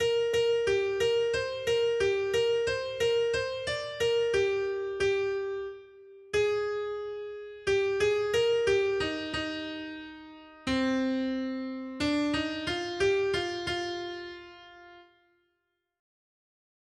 Noty Štítky, zpěvníky ol172.pdf responsoriální žalm Žaltář (Olejník) 172 Skrýt akordy R: Bože, ve své dobrotě ses postaral o chudáka. 1.